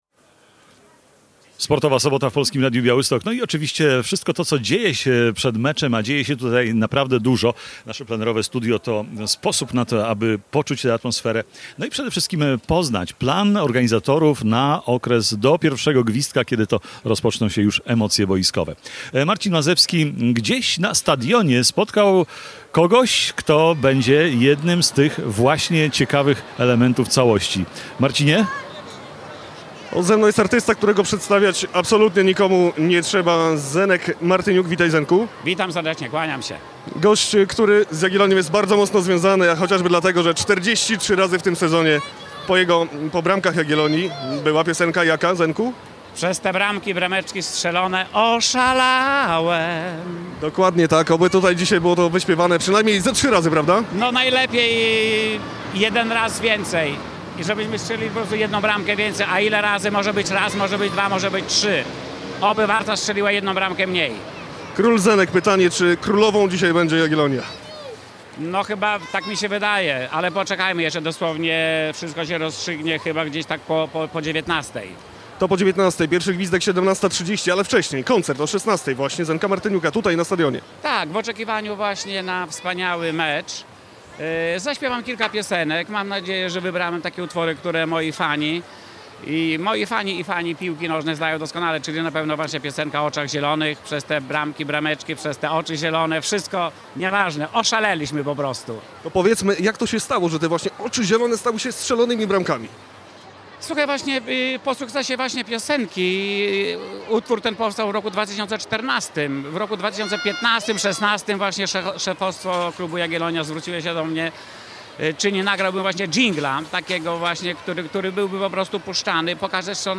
Z Zenonem Martyniukiem rozmawia
Nasz program rozpoczęliśmy o 14:00 z plenerowego studia na stadionie miejskim w Białymstoku.